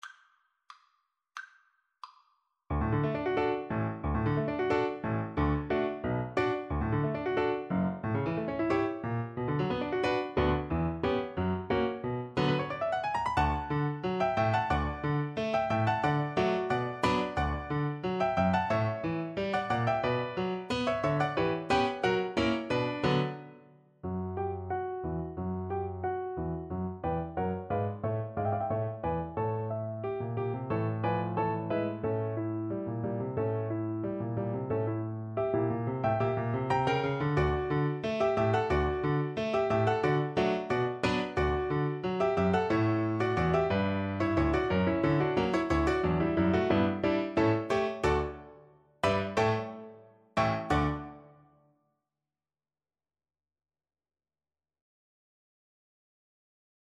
2/4 (View more 2/4 Music)
Classical (View more Classical Violin Music)